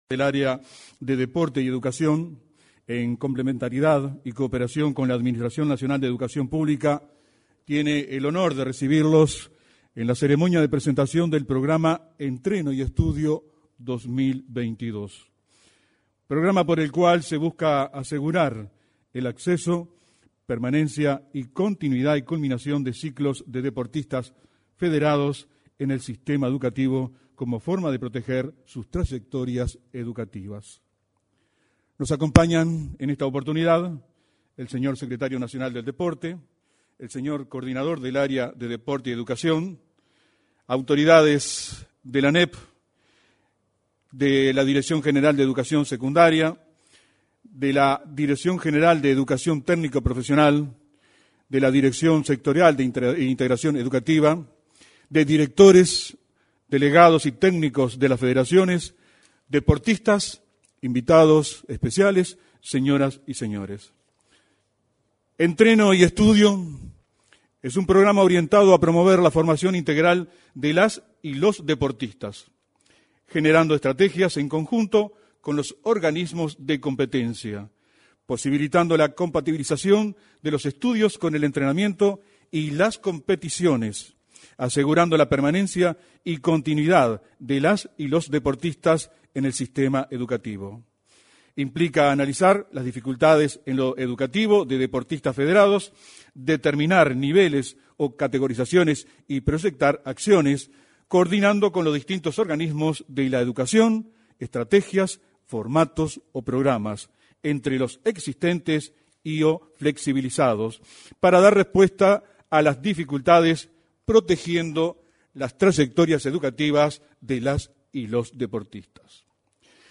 Este lunes 28, en el salón de actos de la Torre Ejecutiva, se realizo la presentación del programa Entreno y Estudio.